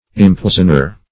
Impoisoner \Im*poi"son*er\, n.